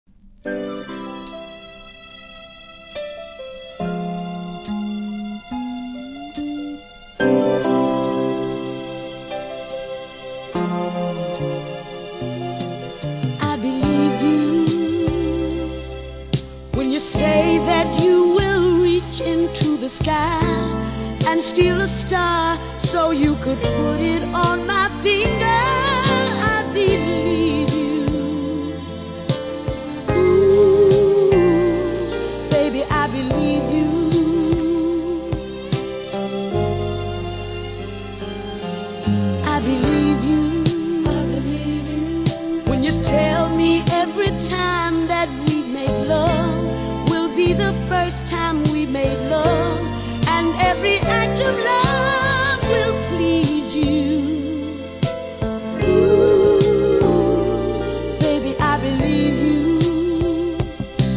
70'S FEMALE